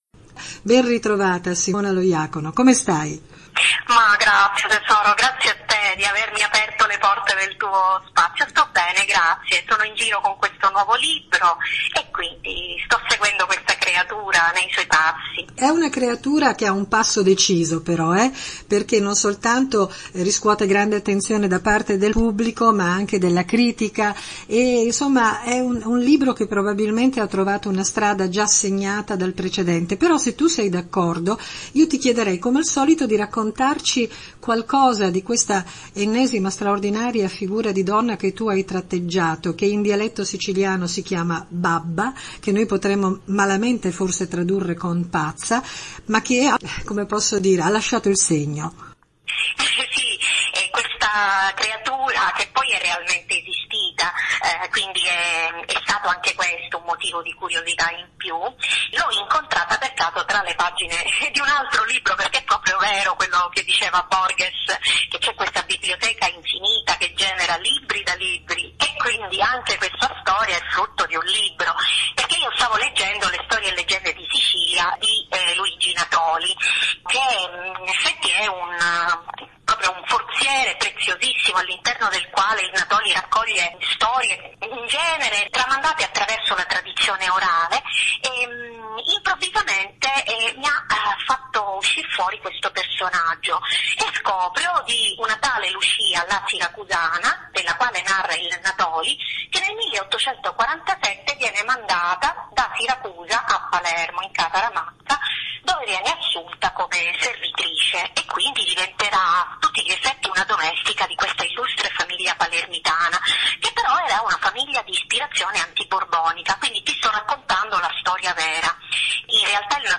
chiacchierata